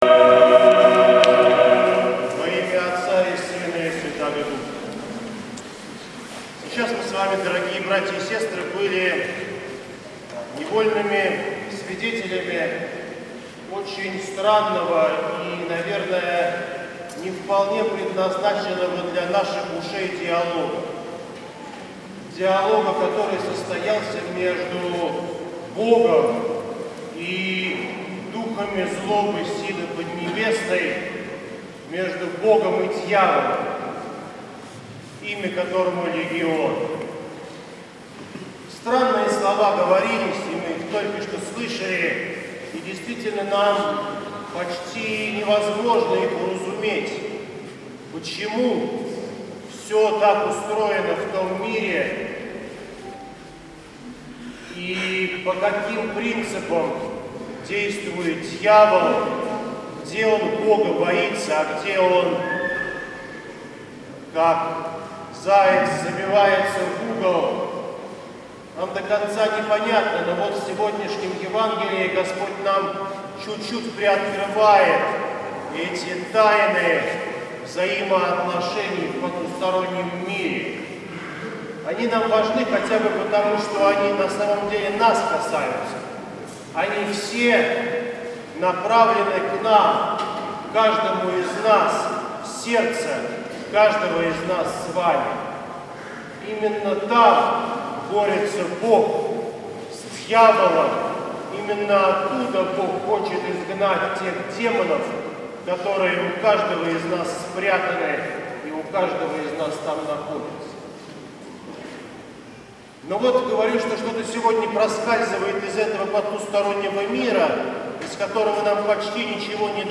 всенощное бдение 10 ноября 2018г.